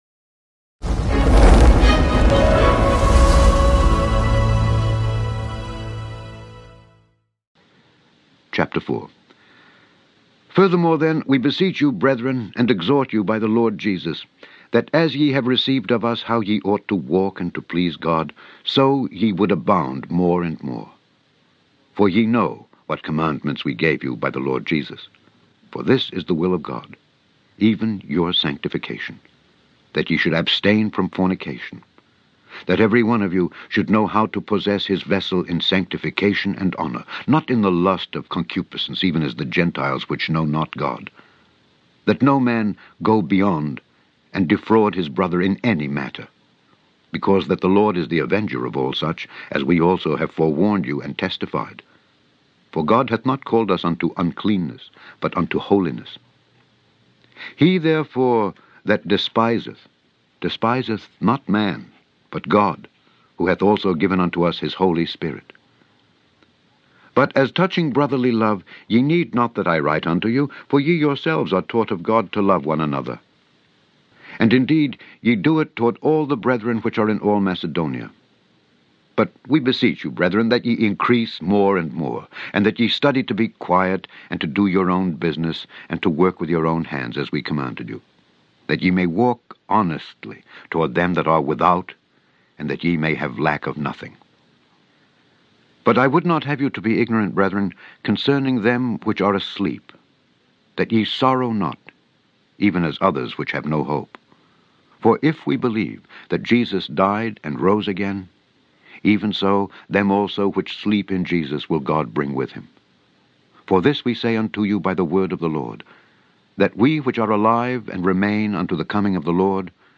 Daily Bible Reading: I Thessalonians 4-5
Click on the podcast to hear Alexander Scourby read I Thessalonians 4-5